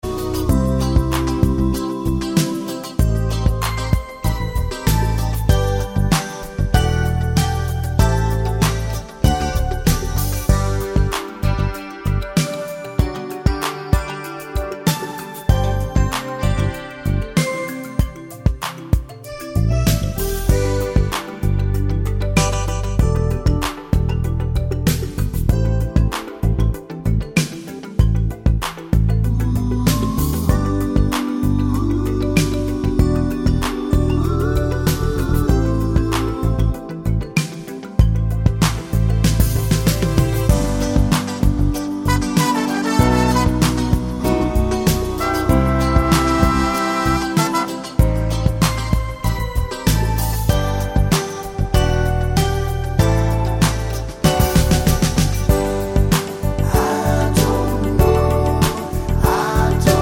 no Backing Vocals Pop (1990s) 4:28 Buy £1.50